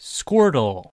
Captions English A voice reading the name of the fictional species of Pokémon Squirtle
Squirtle_Voice_Line.ogg